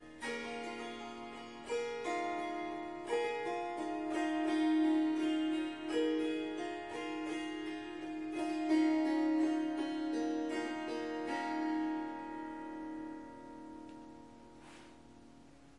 Swarmandal印度竖琴曲谱 " 竖琴弹奏曲谱7
这个奇妙的乐器是Swarmandal和Tampura的结合。
它被调到C sharp，但我已经将第四个音符（F sharp）从音阶中删除了。
这些片段取自三天不同的录音，因此您可能会发现音量和背景噪音略有差异。一些录音有一些环境噪音（鸟鸣，风铃）。
Tag: 竖琴 弦乐 旋律 Swarsangam 民族 Swarsangam 印度 即兴重复段 Surmandal Swarmandal 旋律